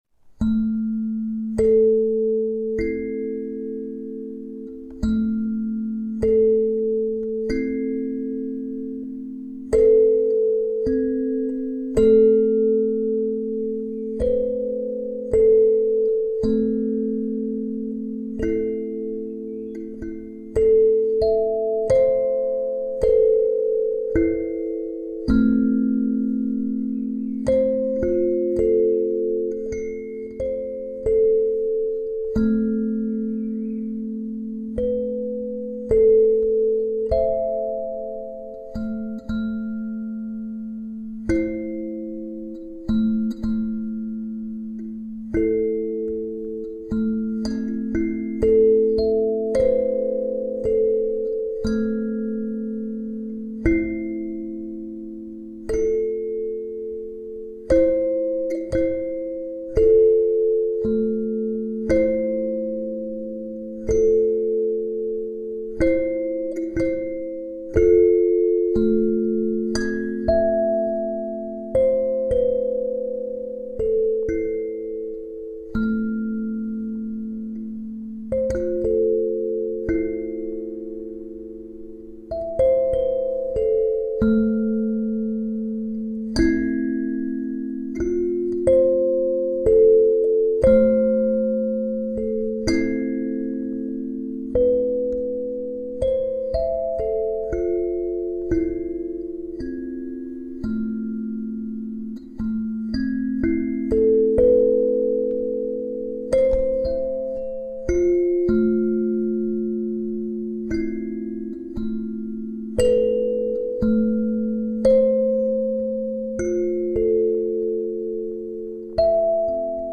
Film zum Malen, Sansula-Foto und Sansula-Musik
und lauschst dabei den Sansula-Klängen und malst dazu dein Bild!
Sansula-mitHall.mp3